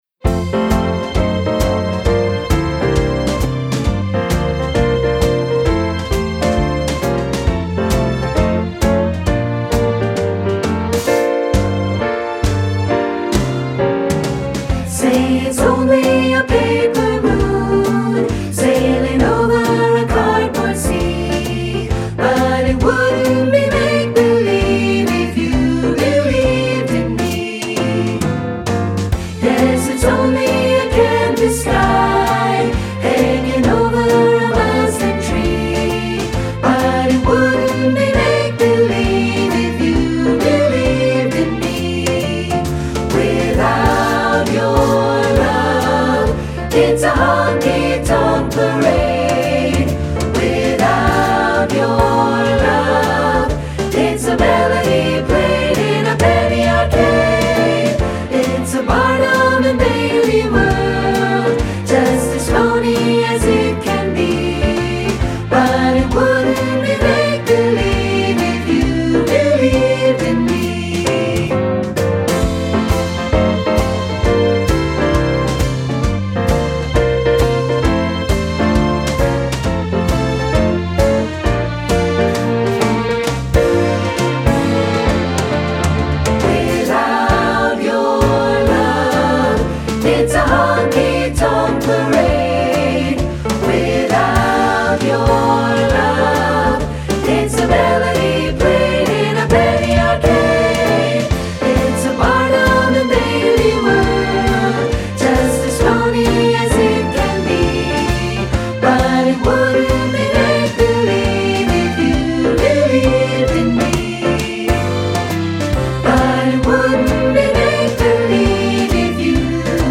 Choral Jazz
3 Part Mix
3-Part Mixed Audio